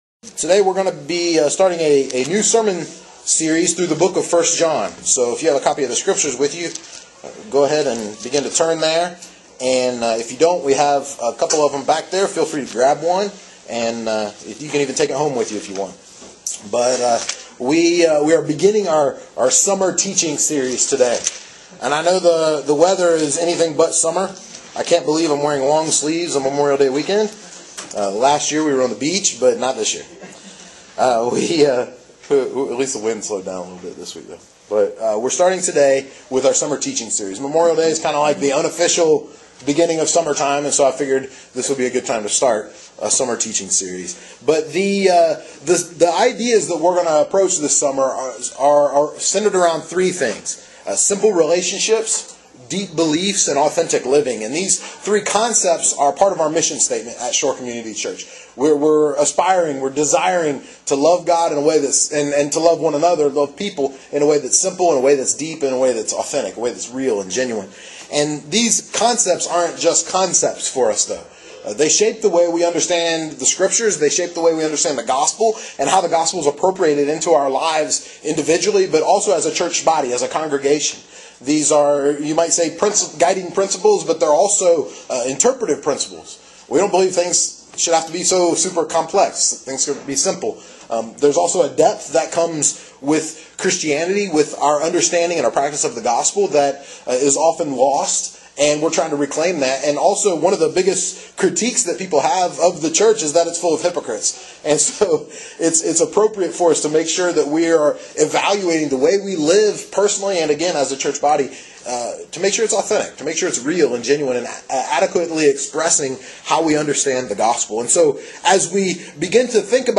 preaches through the New Testament letter of First John during the summer of 2013